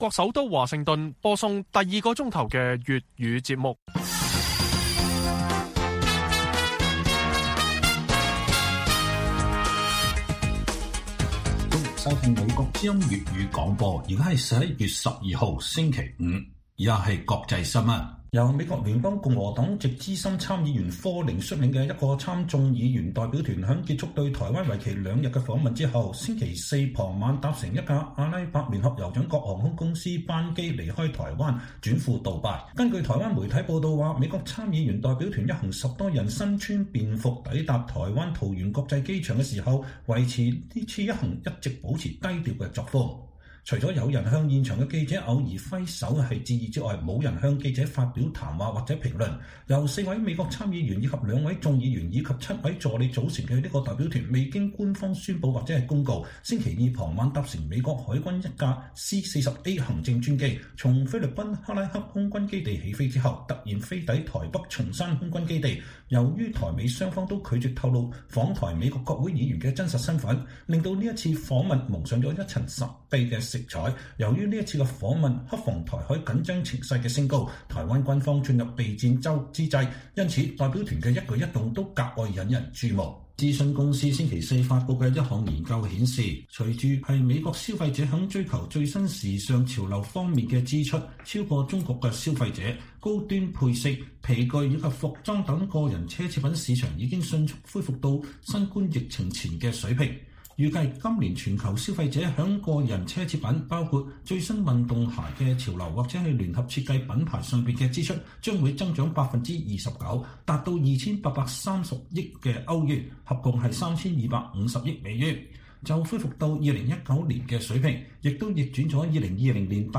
粵語新聞 晚上10-11點:香港M+博物館國安法政治審查爭議中開幕